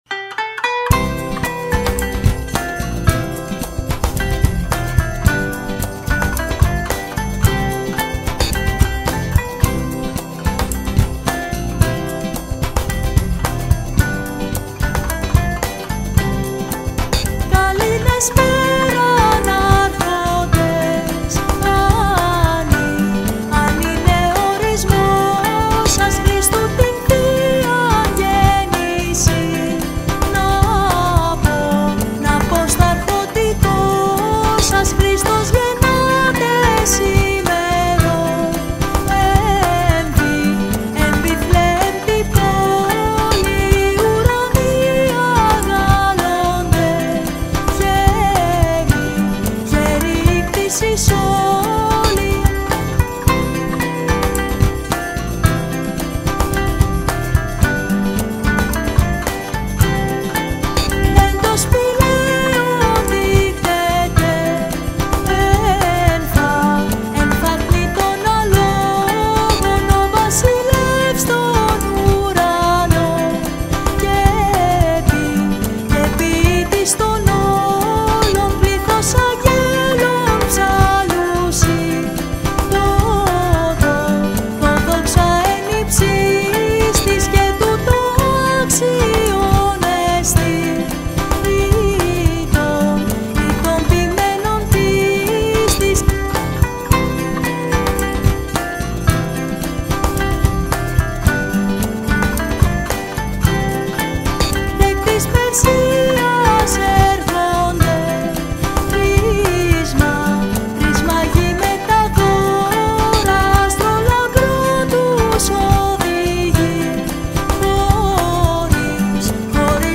Κάλαντα-Χριστουγέννων-_-Καλήν-Εσπέραν-Άρχοντες-_-Greek-christmas-carol.mp3